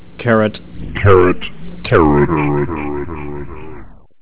carrotcarrotcarrot.au